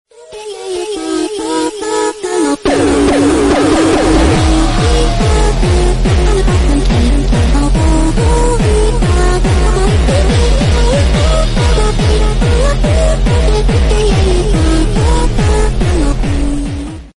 2011 Canton Lake Ef3 Tornado Sound Effects Free Download
2011 Canton Lake ef3 tornado